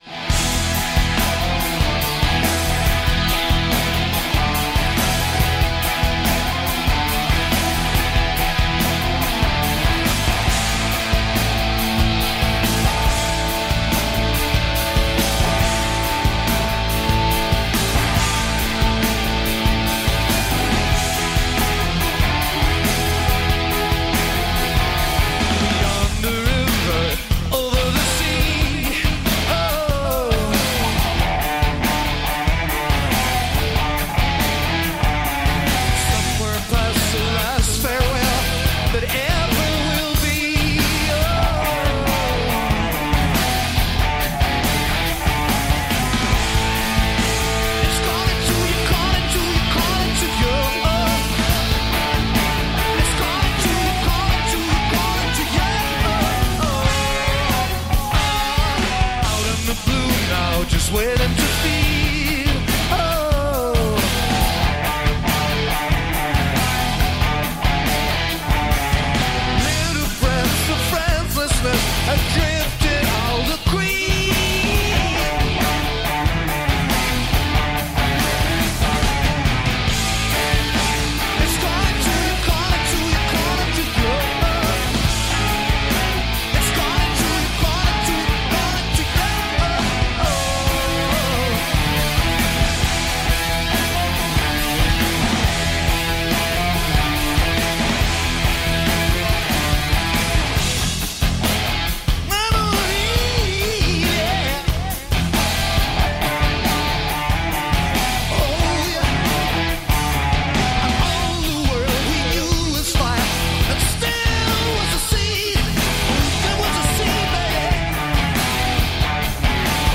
Live At Glastonbury 1993